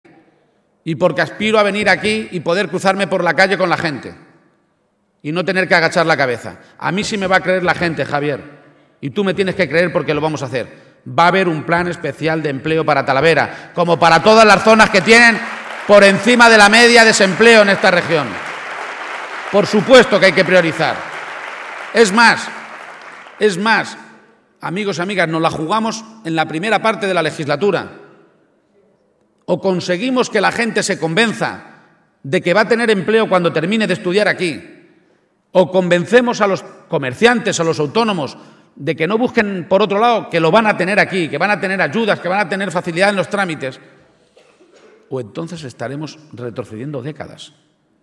El candidato del PSOE a la Presidencia de Castilla-La Mancha, Emiliano García-Page, aseguraba hoy en Talavera de la Reina (Toledo) que “esta tierra no necesita un cambio histórico, ese ya lo hicimos nosotros, sino ir todos a una el día 24 a cambiarla”.